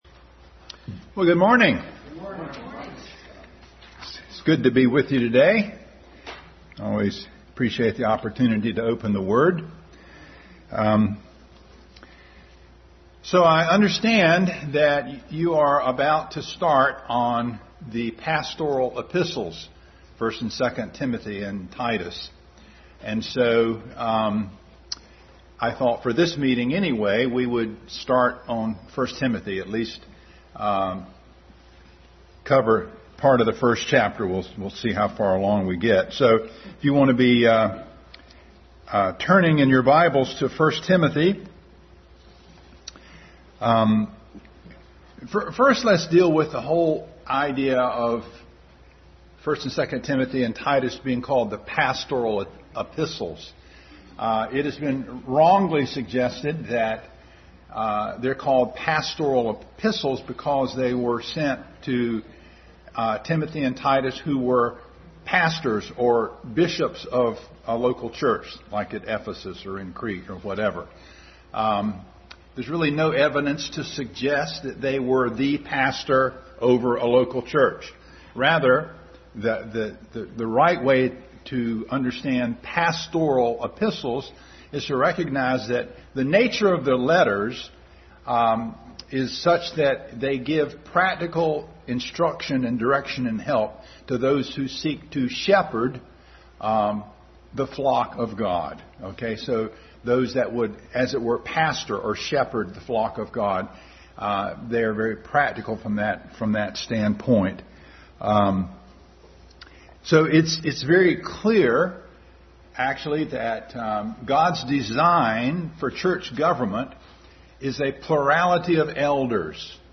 Adult Sunday School continued study in 1 Timothy.
Service Type: Sunday School